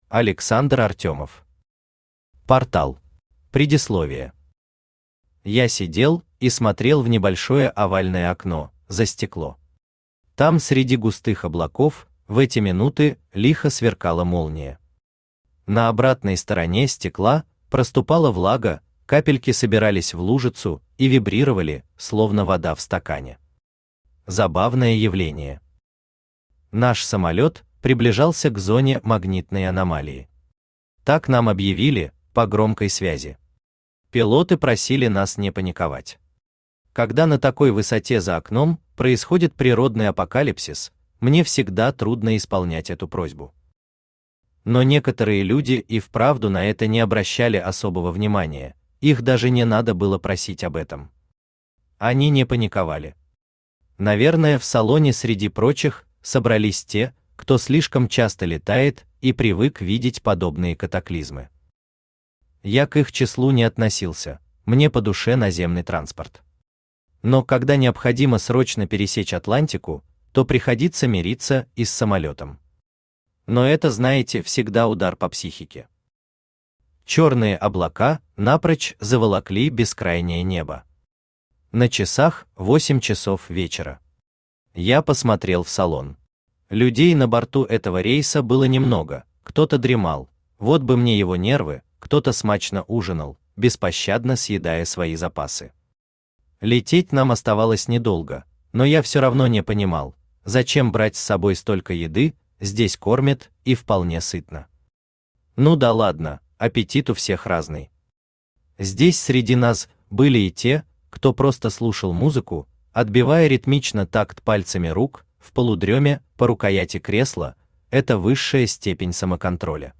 Aудиокнига Портал Автор Александр Артемов Читает аудиокнигу Авточтец ЛитРес.